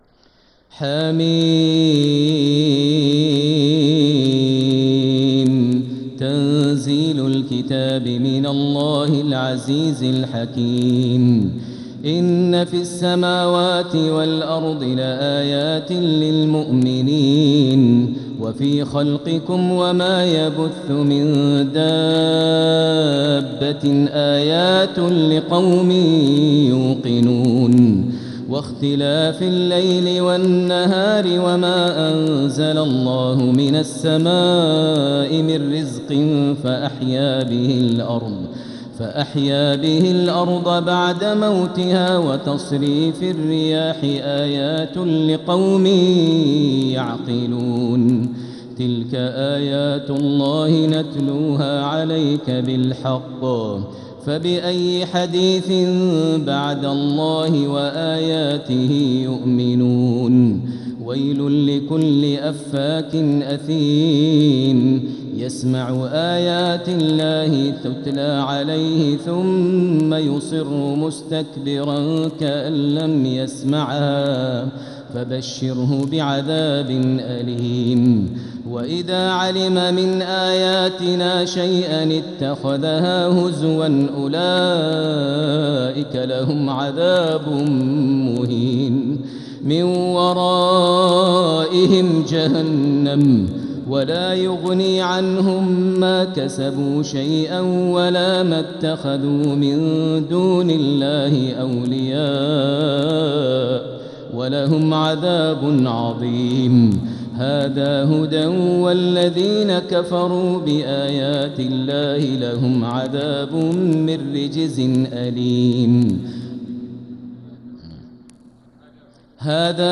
سورة الجاثية | مصحف تراويح الحرم المكي عام 1446هـ > مصحف تراويح الحرم المكي عام 1446هـ > المصحف - تلاوات الحرمين